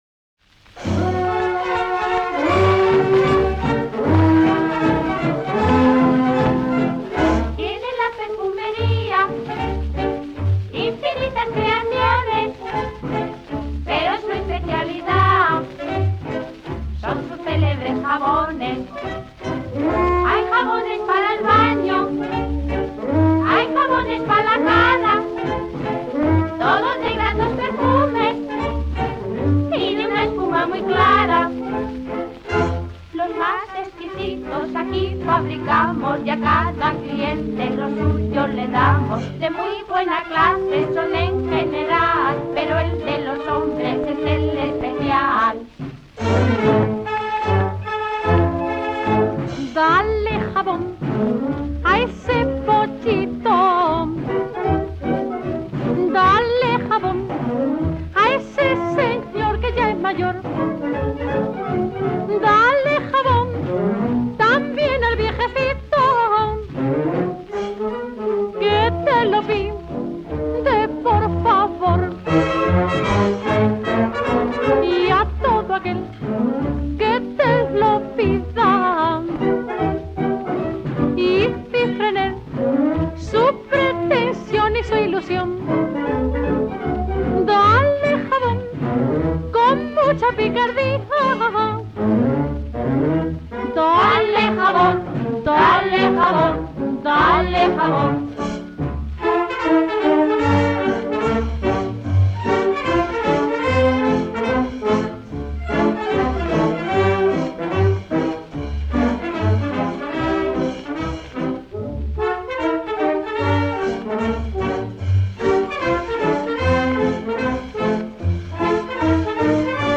foxtrot
78 rpm